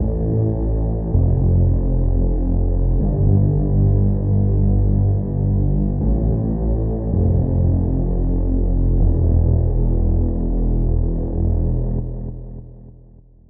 ambience-2.ogg